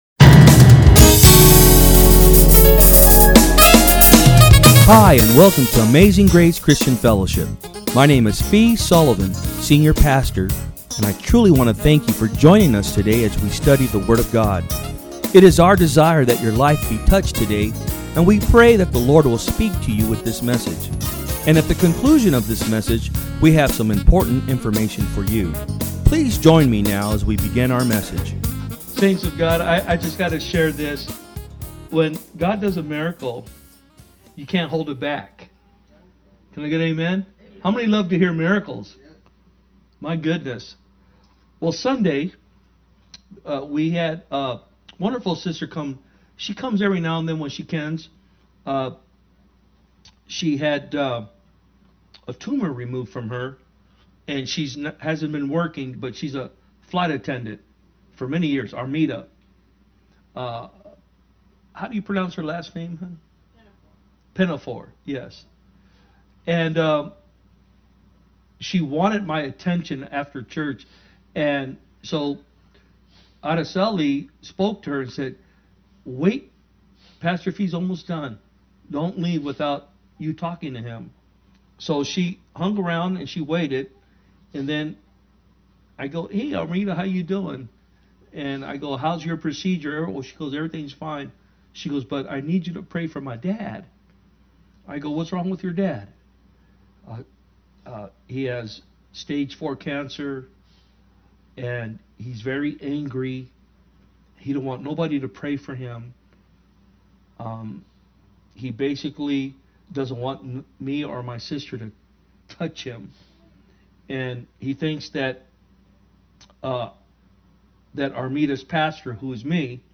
From Service: "Wednesday Pm"